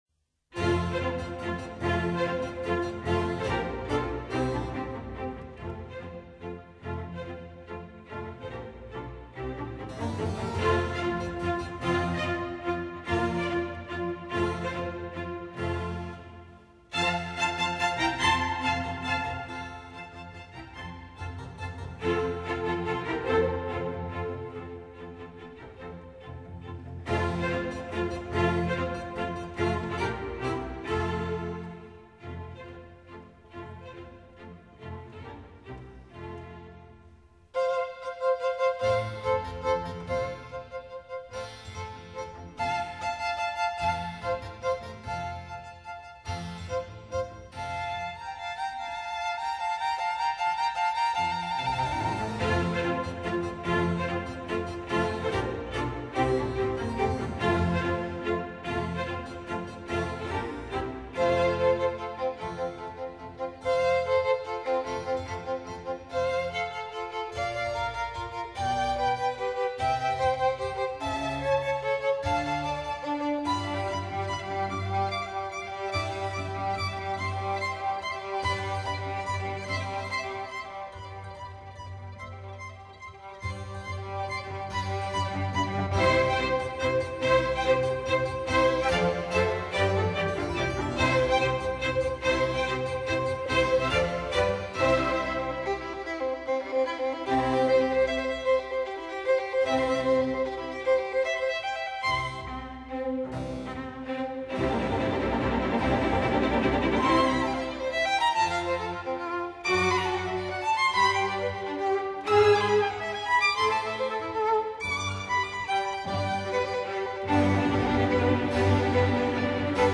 III．Allegro
第三乐章：狩猎，快板
几个插部仍然主要是由小提琴独奏奏出，说明猎物的奔逃和逐渐衰竭而死。